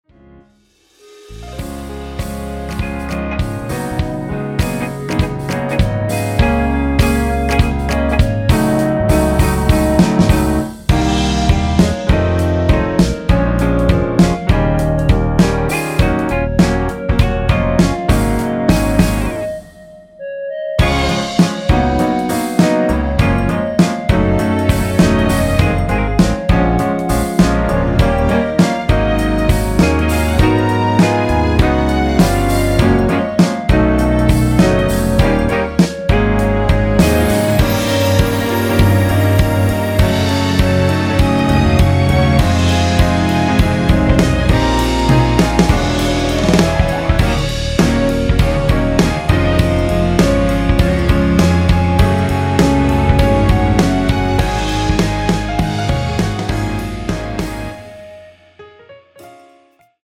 원키에서(-2)내린 (1절+후렴)으로 진행되는 멜로디 포함된 MR입니다.
Db
앞부분30초, 뒷부분30초씩 편집해서 올려 드리고 있습니다.
중간에 음이 끈어지고 다시 나오는 이유는